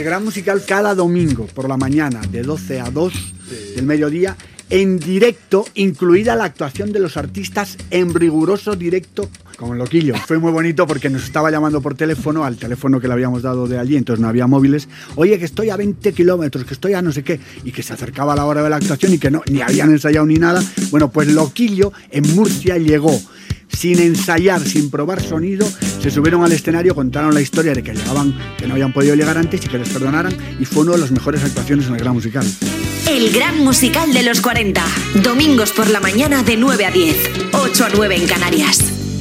El grup musical "Presuntos implicados" i Ana Torroja de "Mecano" parlen de Joaquín Luqui.
Musical